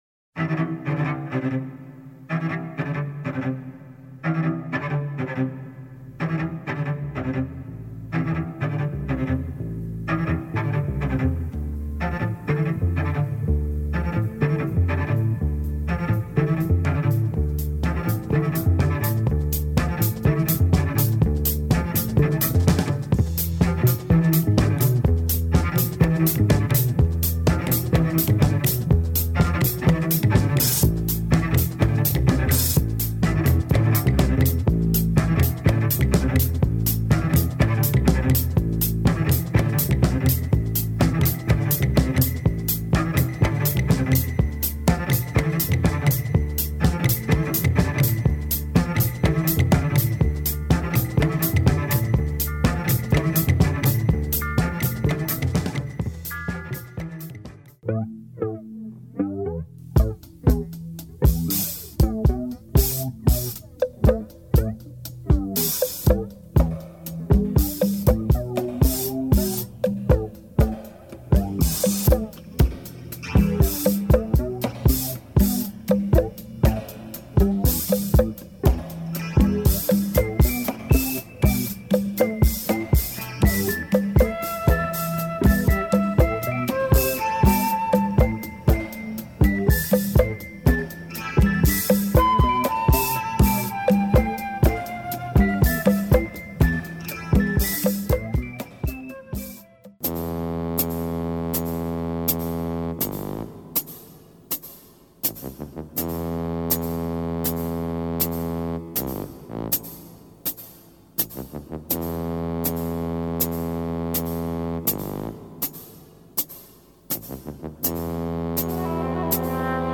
is more on the funky / light electronic side
has drama breaks with flute
has a soft light groove
is a weird funk tune
has drama beats and electronic FX
Beat LP